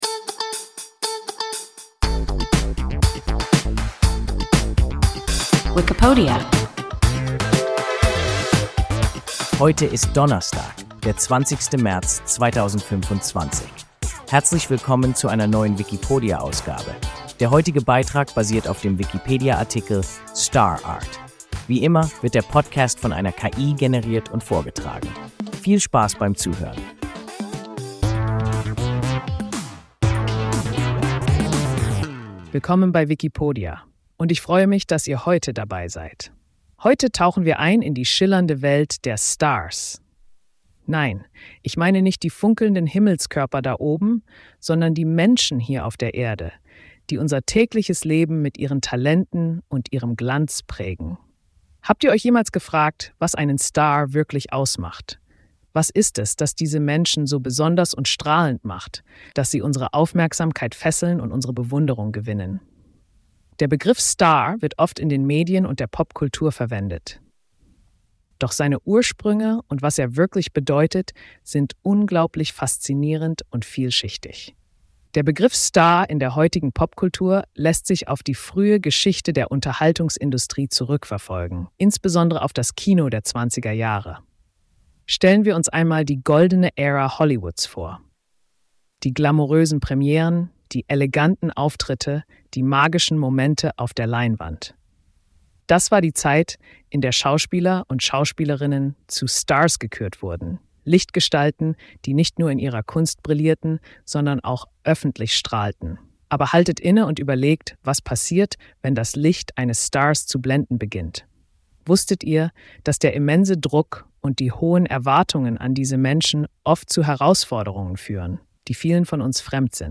Star (Art) – WIKIPODIA – ein KI Podcast